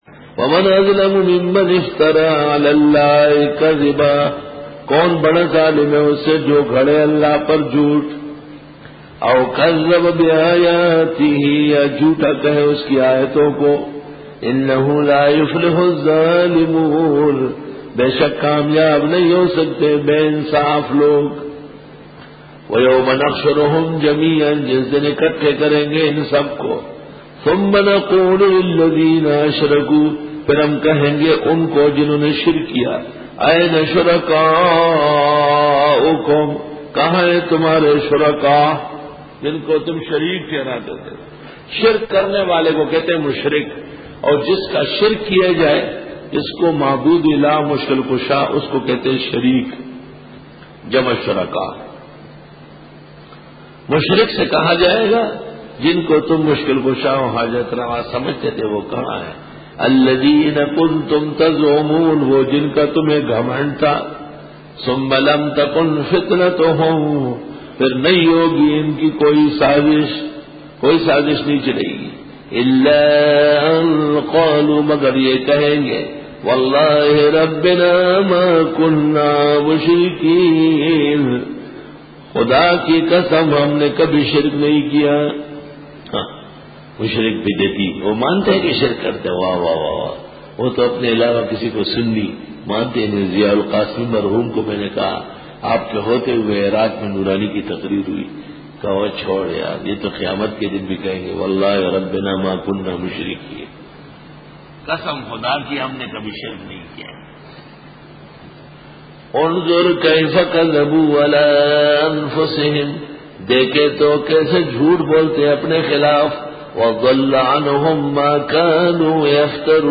Dora-e-Tafseer 2006